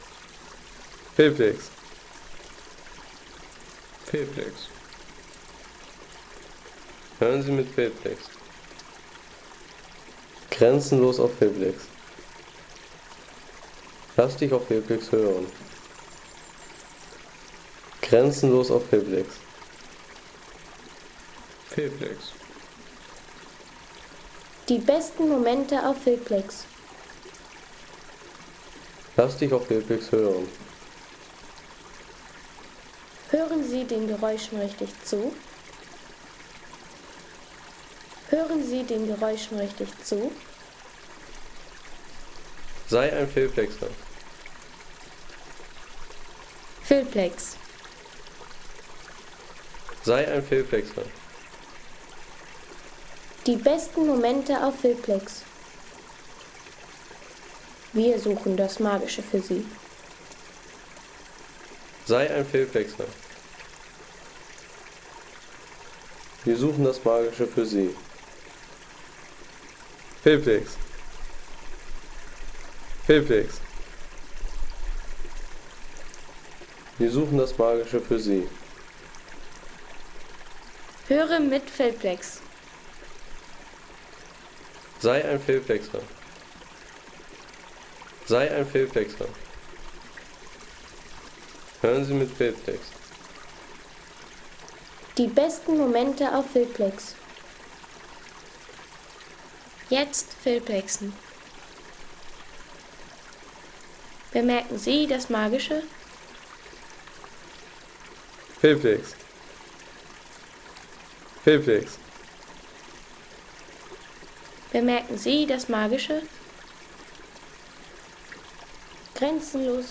Harz: Authentische Steinerne Renne an der Holtemme, Granit-Stromschnellen und Wasserfälle nahe dem Brocken.
Ein Stück Holtemme-Oberlauf: Granit-Stromschnellen, kleine Wasserfälle und dieser Brocken-nahe Gebirgsfluss-Vibe.